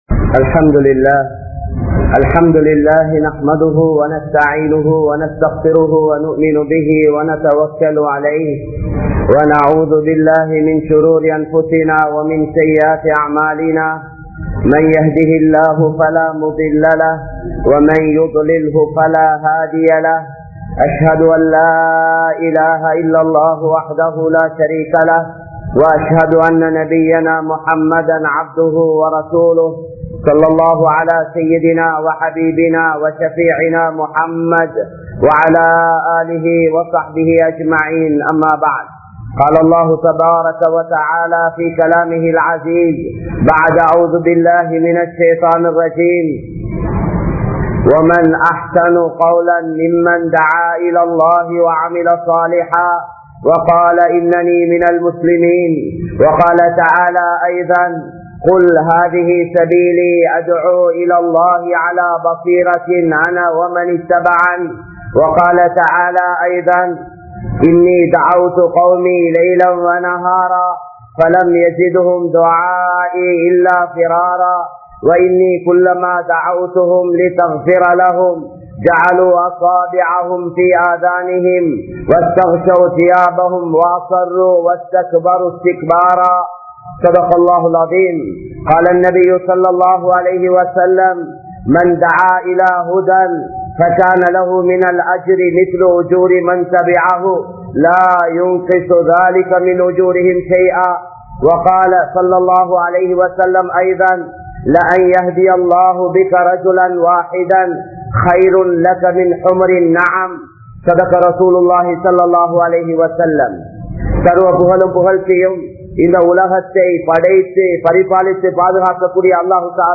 Dawathum Athan Mukkiyaththuvamum(தஃவத்தும் அதன் முக்கியத்துவமும்) | Audio Bayans | All Ceylon Muslim Youth Community | Addalaichenai
Colombo04,Bambalapitiya, Muhiyadeen Jumua Masjith